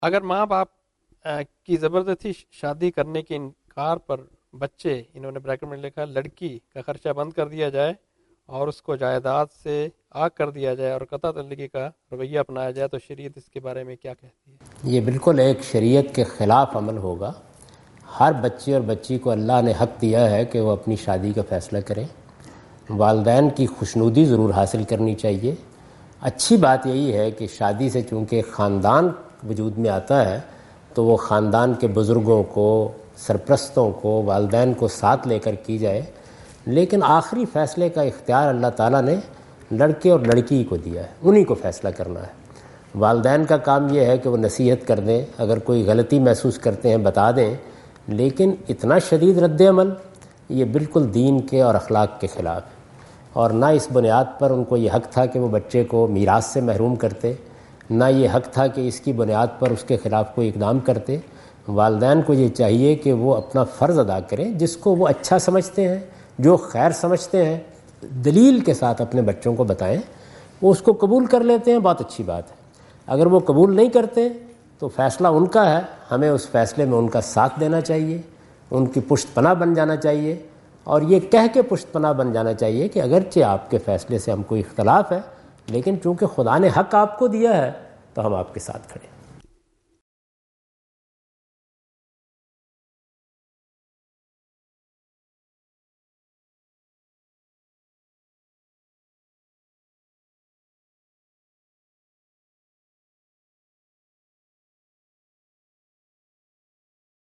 Javed Ahmad Ghamidi answer the question about "forced marriage and inheritance" in Macquarie Theatre, Macquarie University, Sydney Australia on 04th October 2015.